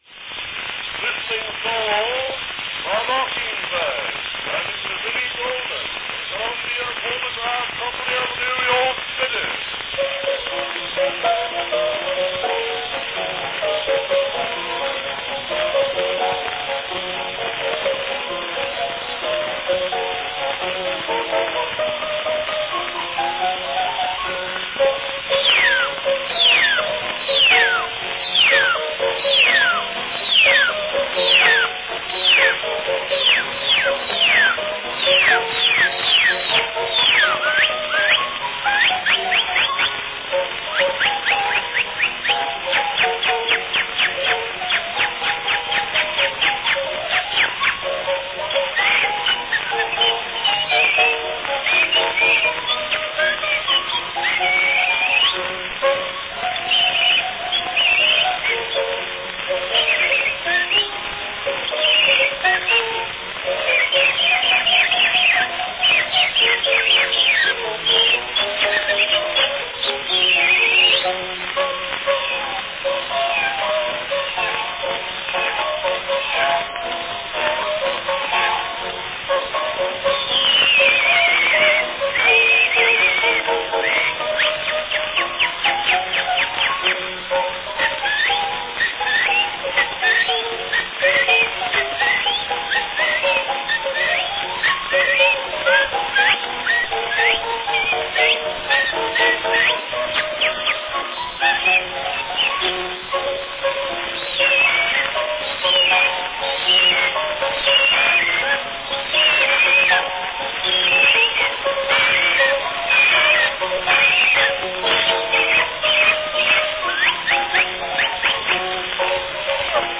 Cylinder of the Month
a fun whistling-specialty of The Mocking Bird
Category Whistling solo
and this selection as "Unique Whistling"